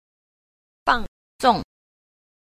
1. 放縱 – fàngzòng – phóng túng